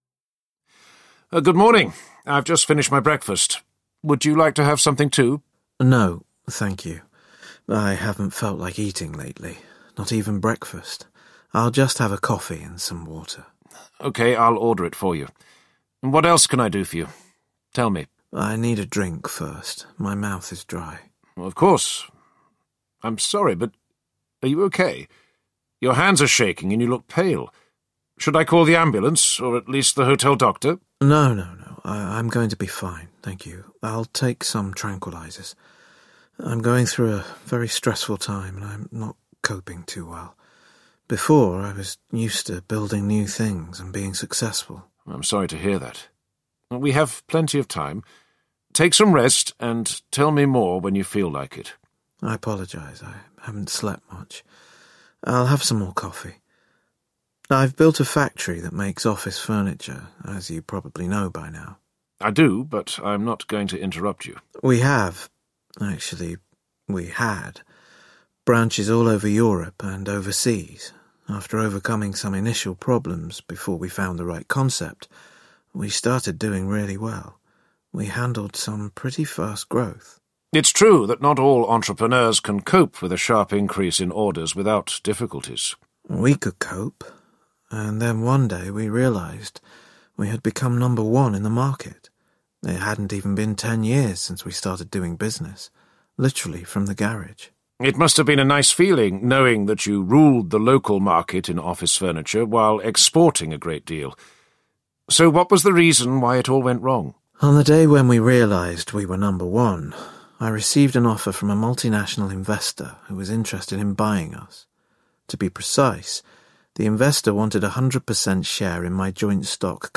Business Risk Buster Intervenes 10 audiokniha
Ukázka z knihy